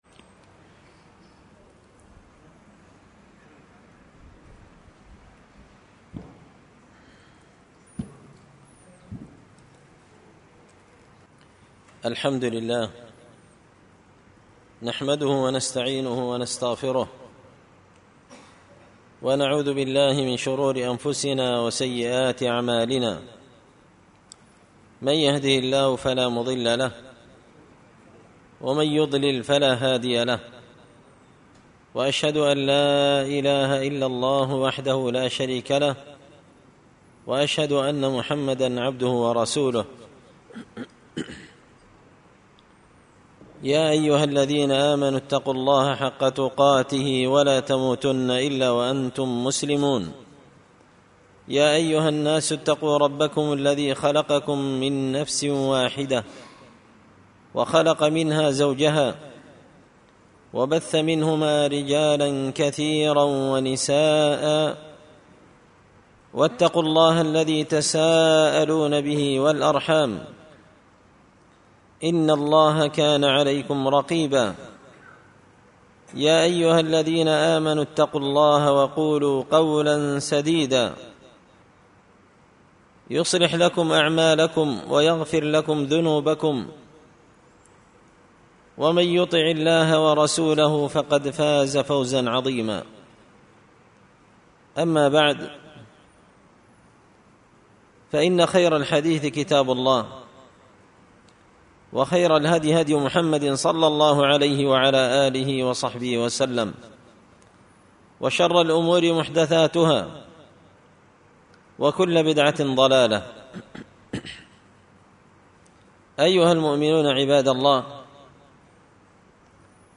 محاضرة بين مغرب وعشاء في الغيل
محاضرة-بين-مغرب-وعشاء-في-الغيل.mp3